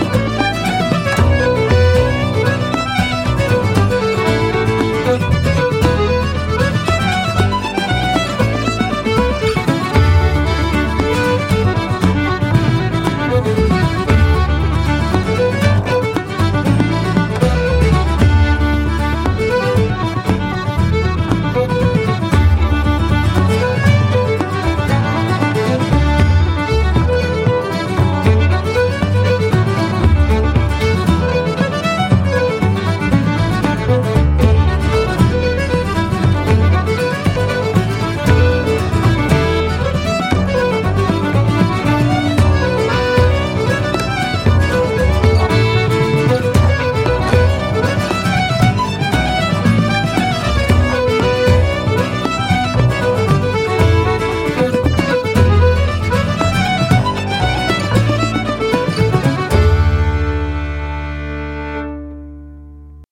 Bodhran
Tenor Banjo
fiddle, vocals
guitar, mandolin, vocals